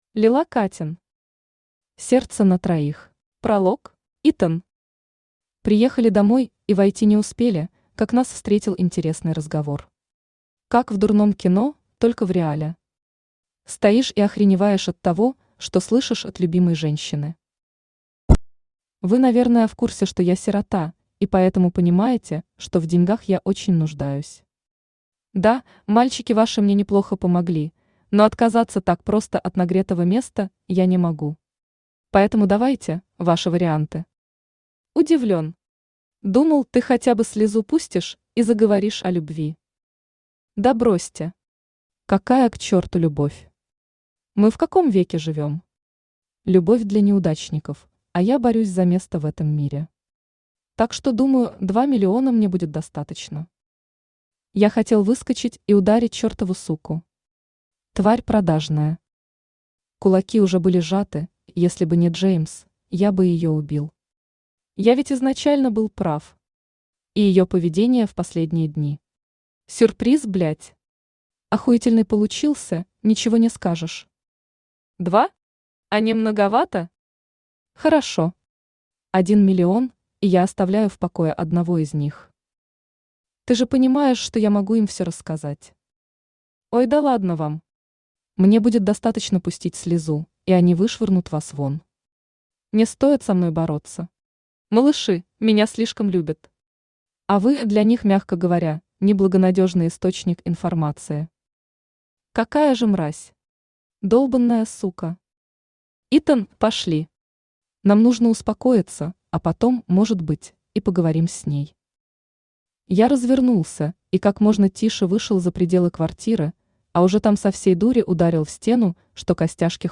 Аудиокнига Сердце на троих | Библиотека аудиокниг
Aудиокнига Сердце на троих Автор Лила Каттен Читает аудиокнигу Авточтец ЛитРес.